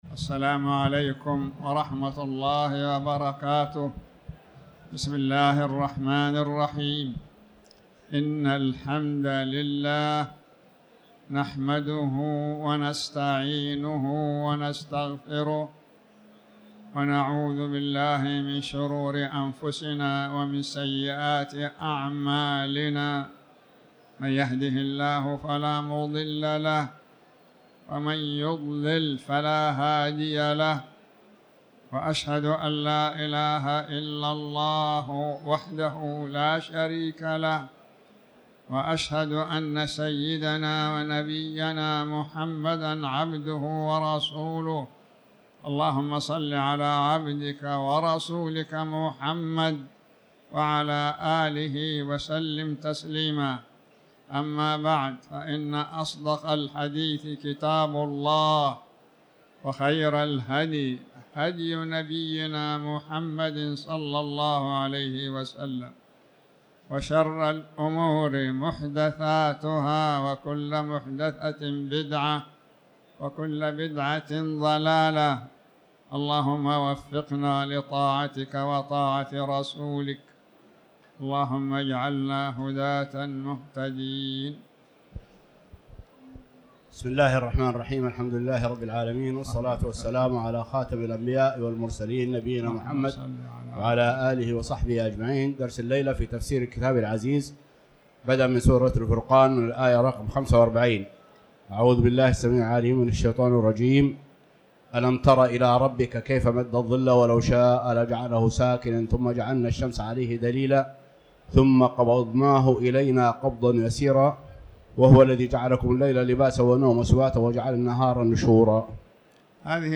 تاريخ النشر ٣ شعبان ١٤٤٠ هـ المكان: المسجد الحرام الشيخ